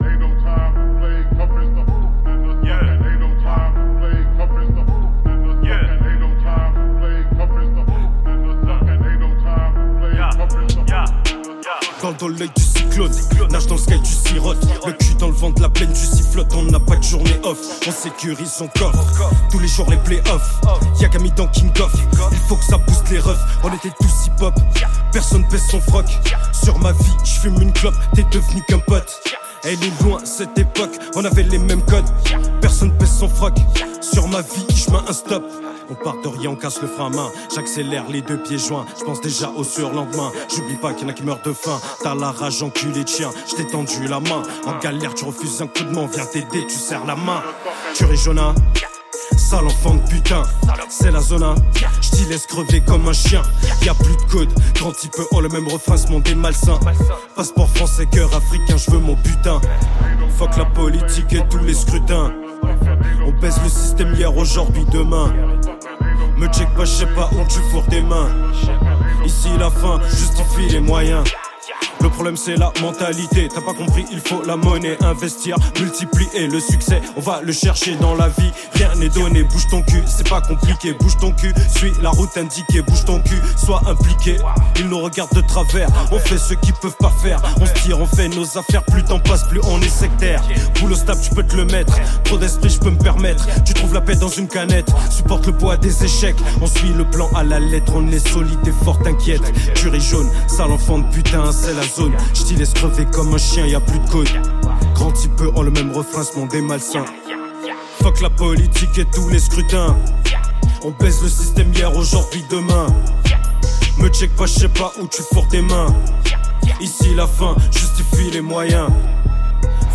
Genre : HipHop